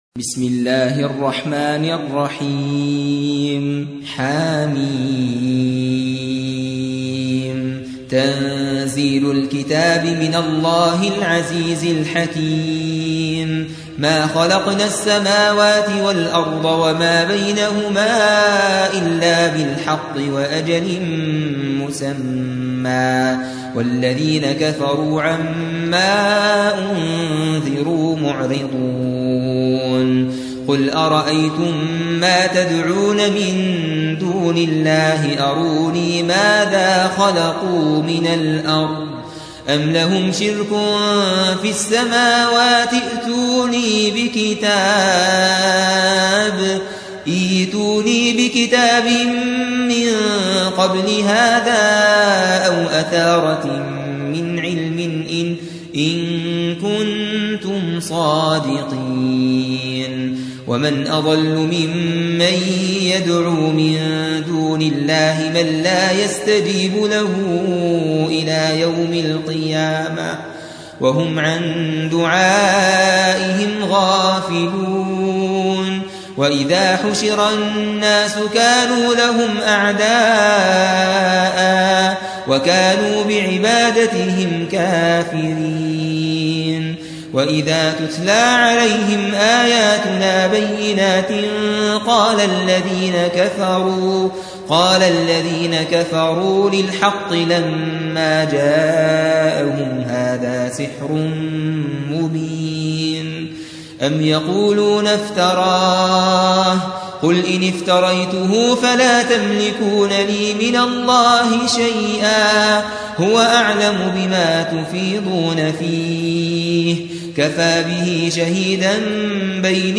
46. سورة الأحقاف / القارئ